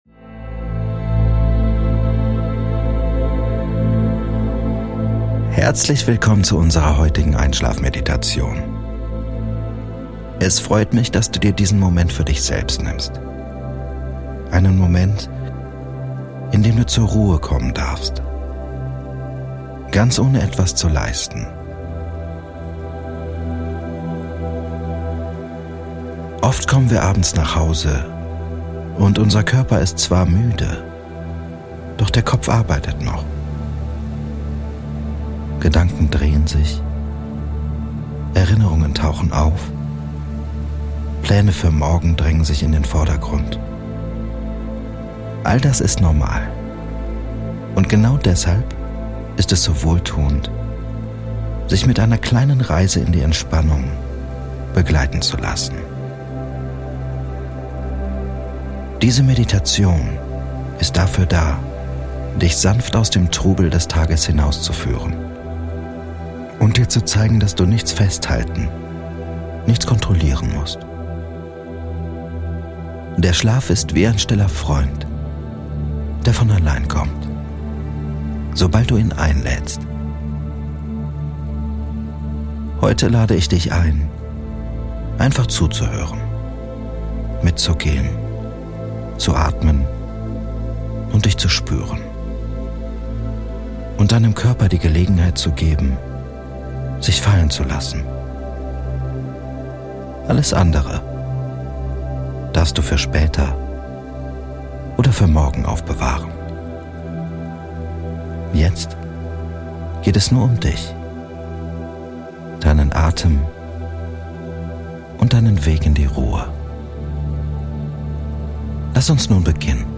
Diese geführte Einschlafmeditation nimmt dich sanft an die Hand und führt dich Schritt für Schritt hinaus aus deinem Kopf und hinein in die wohltuende Entspannung deines Körpers. Mit beruhigenden Atemübungen, einem langsamen Bodyscan und kraftvollen Visualisierungen lernst du, Spannungen loszulassen, deinen Atem als Anker zu nutzen und innere Ruhe zu finden.